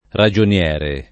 raJonL$re] s. m.; f. -ra — tronc. (nel masch.) davanti a nome o cogn. (anche davanti a consonanti che vorrebbero l’art. lo): ragionier Giuseppe [raJonL$r Ju@$ppe], ragionier Bianchi [raJonLHr bL#jki], ragionier Spagnoli [raJonL$r Span’n’0li] — sim. il cogn. Ragionieri